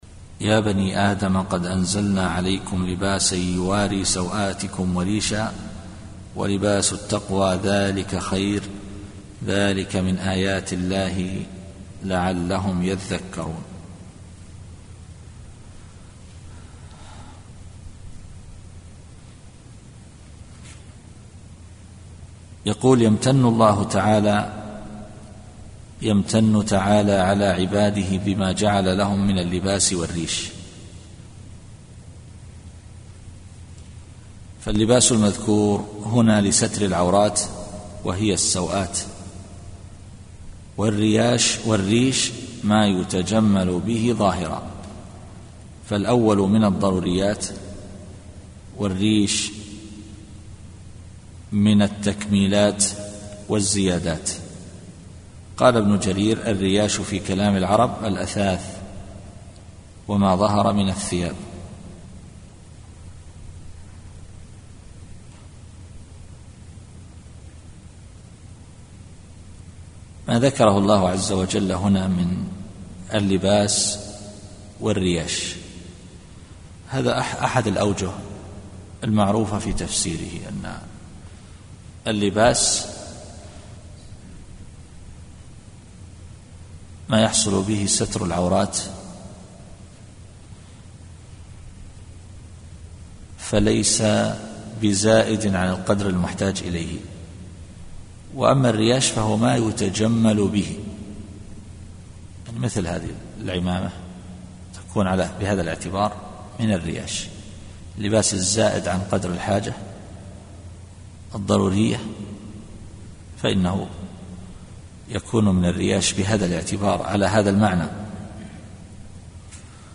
التفسير الصوتي [الأعراف / 26]